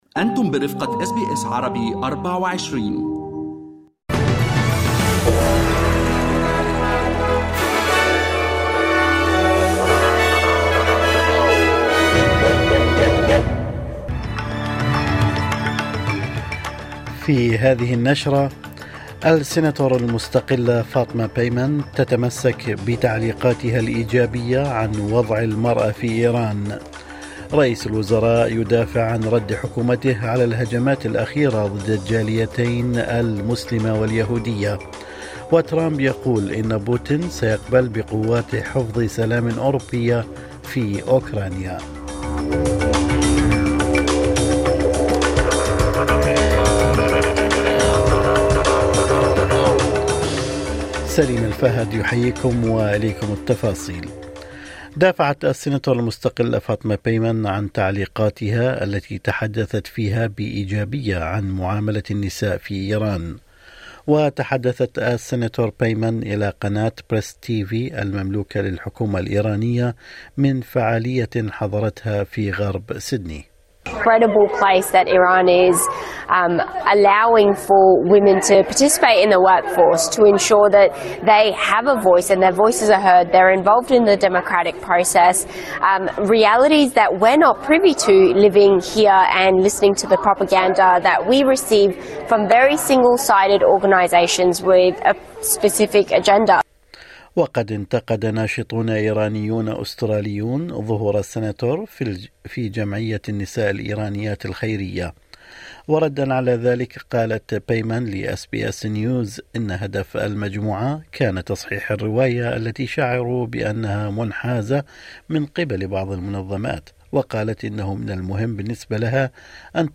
نشرة أخبار الصباح 25/2/2025